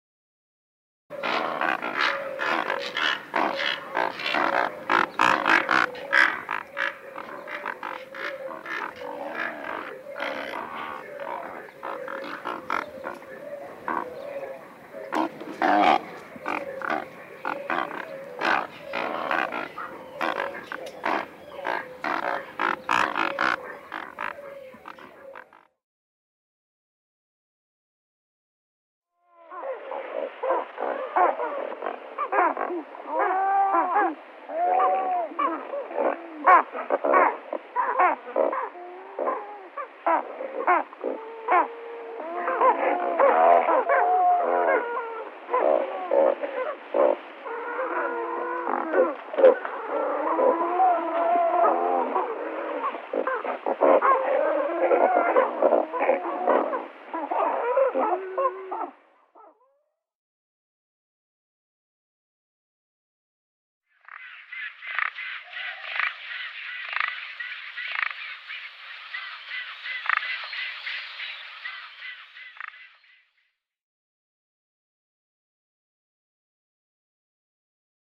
White pelican sound:
You can hear the sound of white pelicans below